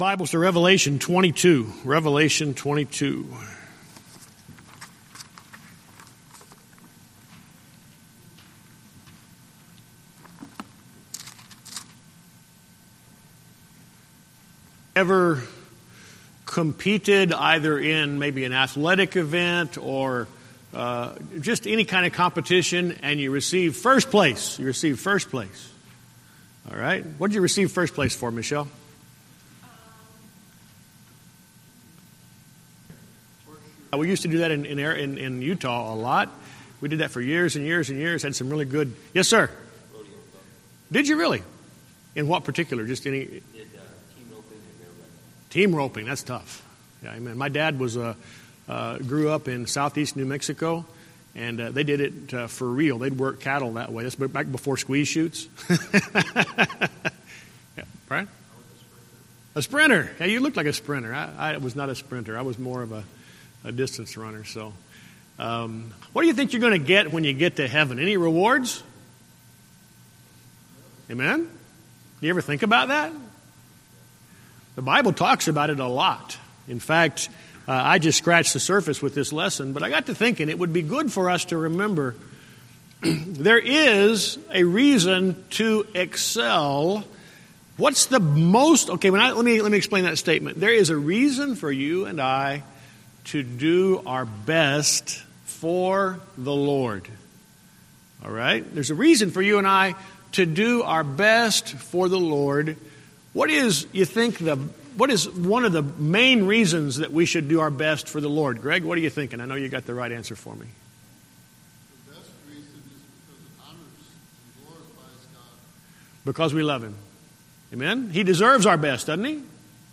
Sermons
Email Details Series: Guest Speaker Date